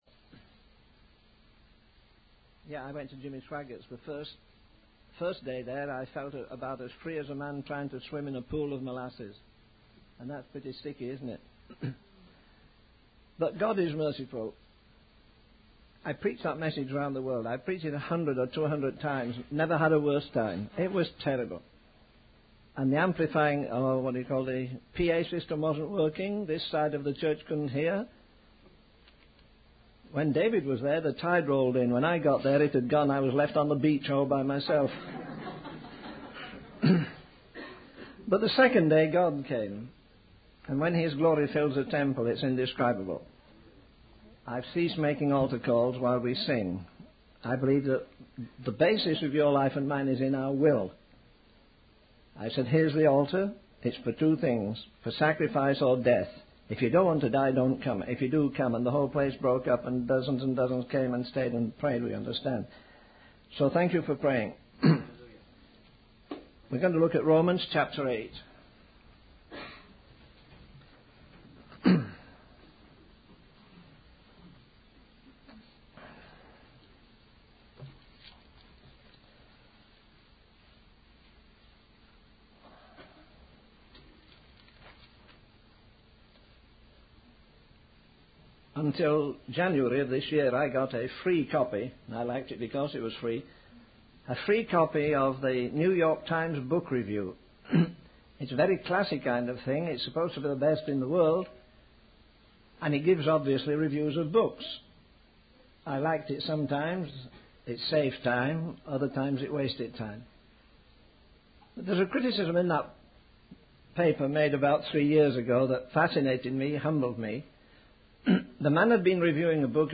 In this sermon, the preacher reflects on the loss of appreciation for the beauty and majesty of the gospel among preachers today.